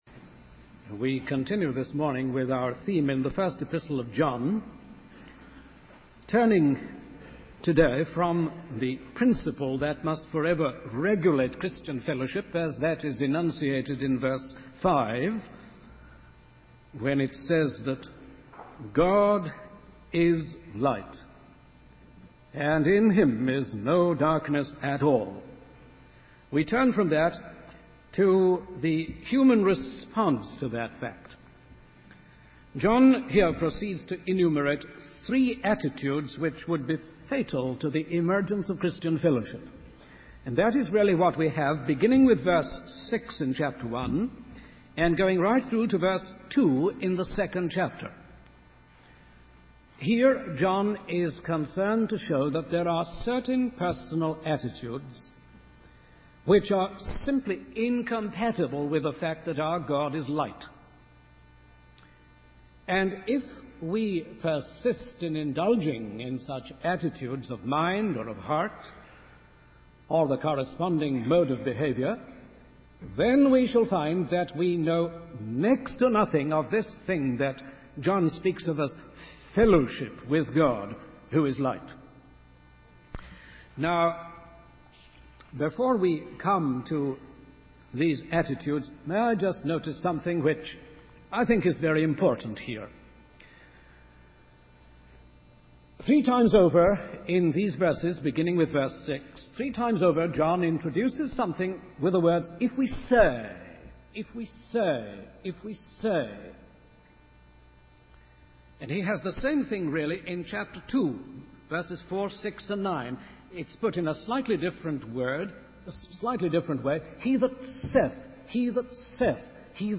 In this sermon, the preacher emphasizes the importance of walking in the light and having transparency and honesty in our lives. He encourages listeners to open their hearts to God and seek His judgment and guidance in all aspects of their lives. The preacher highlights that living in the light means acknowledging and confessing our sins to God, and allowing the blood of Jesus Christ to cleanse us from all sin.